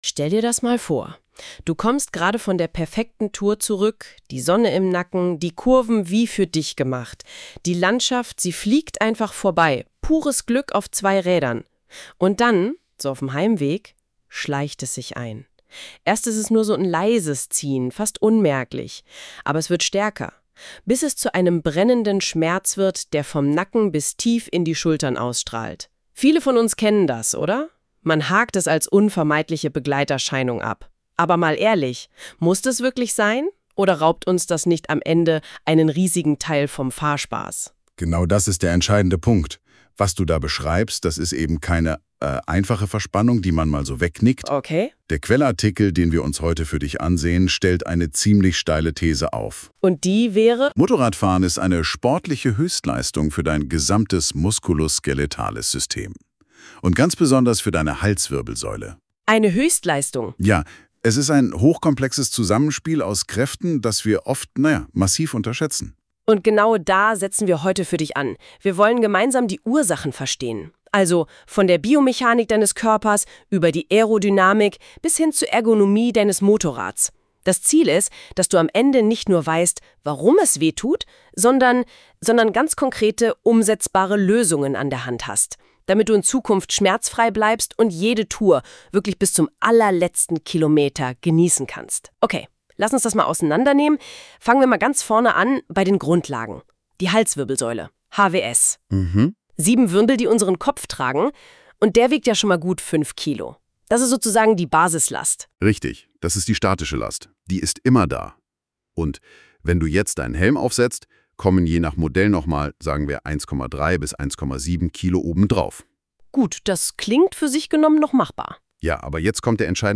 Diese Artikelversion kannst du auch als Audio hören – ideal für unterwegs oder auf langen Motorradtouren.